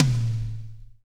-DRY TOM 3-R.wav